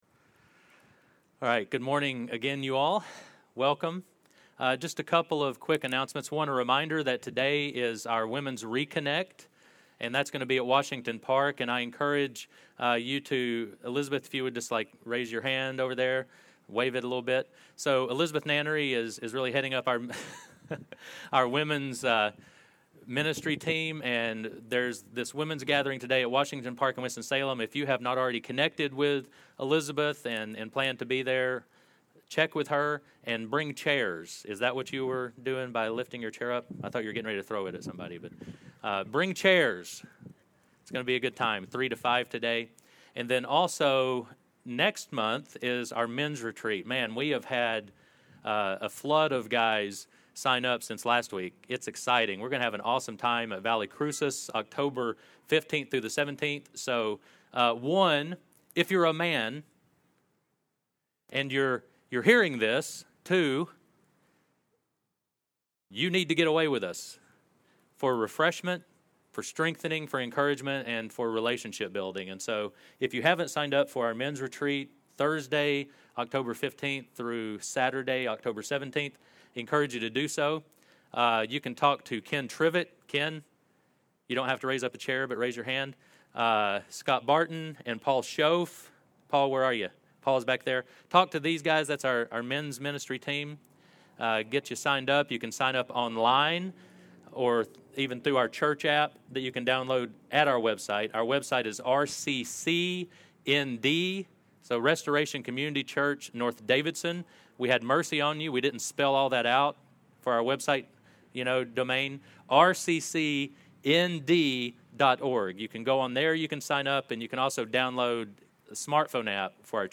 Sermons | Restoration Community Church